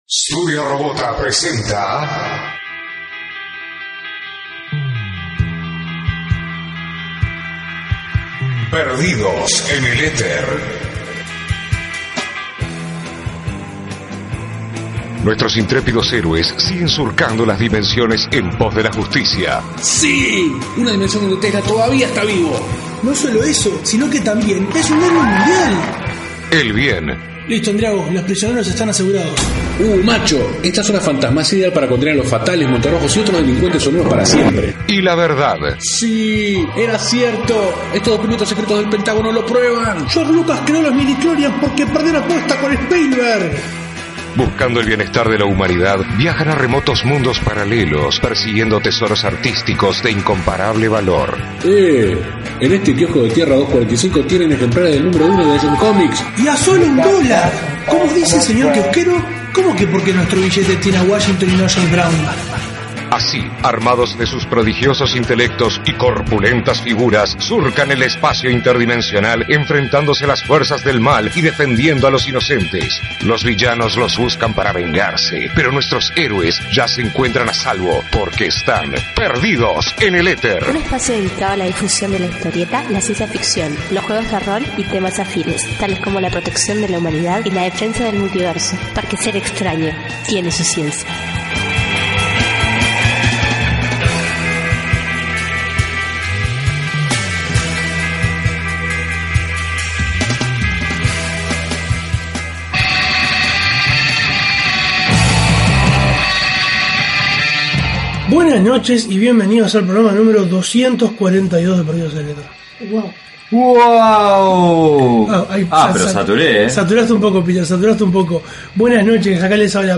Una película muy esperada, con muchas aristas que la hacen un hito en esta era dorada del cine de superhéres. Con su diferentes puntos de vista y niveles de conocimiento del personaje, entre los tres discuten los pormenores de esta cuarta entrega del DCEU.